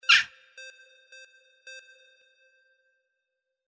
Crow Jackdaw Forest 02
Stereo sound effect - Wav.16 bit/44.1 KHz and Mp3 128 Kbps
previewANM_CROW_FORREST_WBHD02.mp3